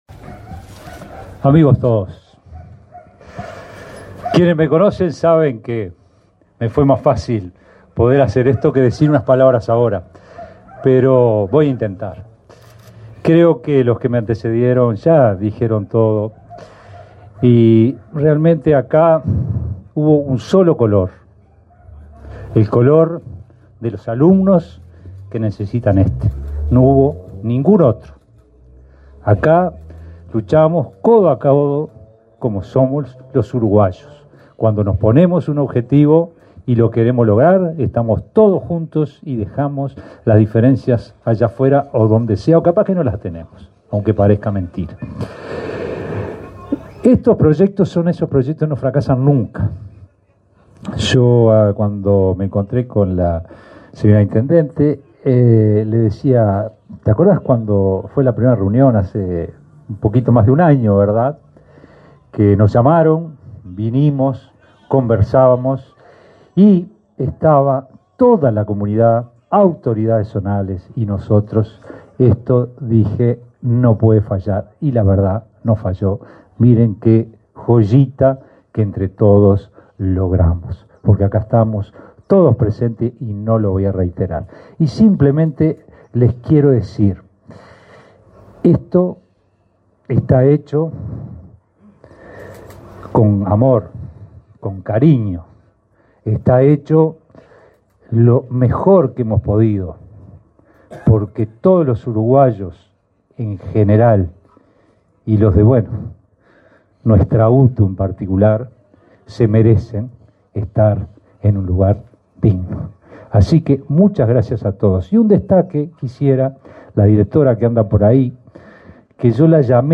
Acto de UTU por inauguración de anexo en Parque del Plata
Acto de UTU por inauguración de anexo en Parque del Plata 20/02/2025 Compartir Facebook X Copiar enlace WhatsApp LinkedIn La Dirección General de Educación Técnico Profesional (DGETP-UTU) realizó, este 20 de febrero en Parque del Plata, la inauguración de un anexo de la escuela técnica de Atlántida. Participaron en el evento el titular de la DGETP-UTU, Juan Pereyra, y el presidente de Administración Nacional de Educación Pública, Juan Gabito.